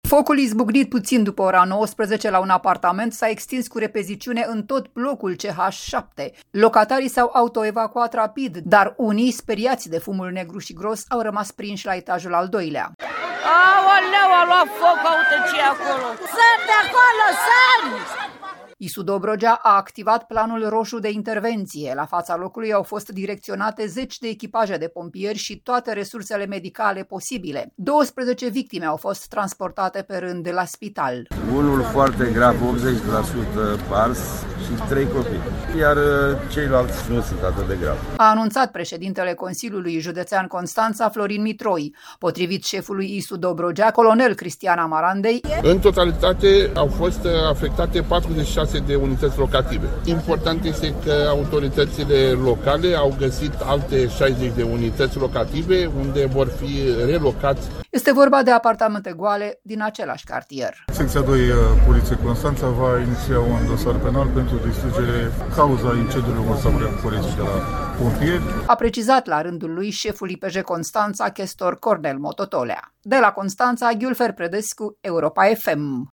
„Aoleu, a luat foc, uite ce-i acolo! Sări de acolo, sări!”, se aud oamenii strigând.